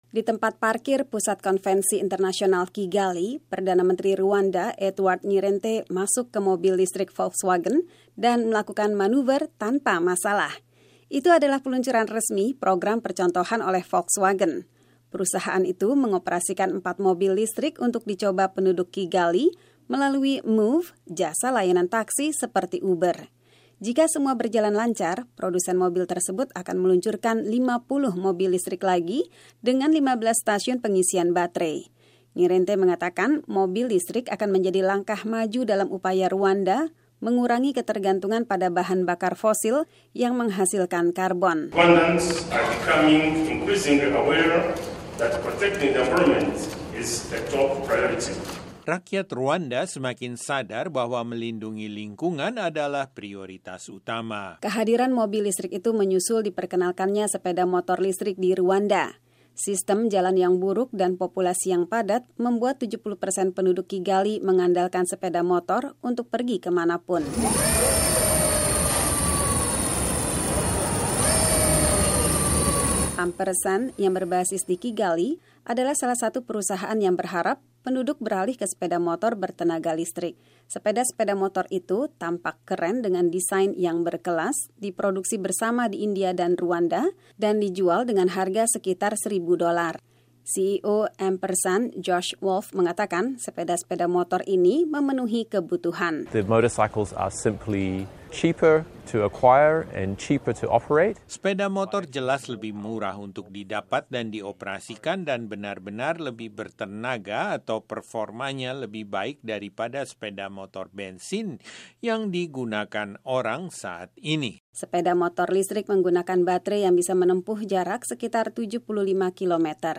menyampaikan laporan ini.